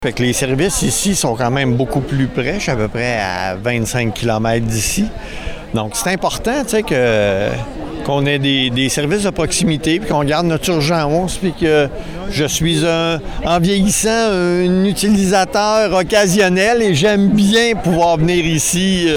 Environ 500 personnes ont pris part à une marche à Fortierville vendredi après-midi pour que le gouvernement maintienne l’intégralité des services à l’urgence.